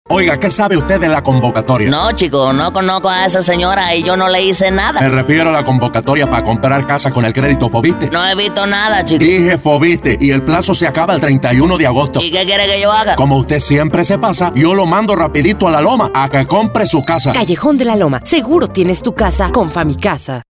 DEMOS
Spot La Loma IIIb.mp3